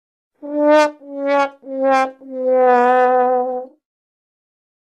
Sad Trombone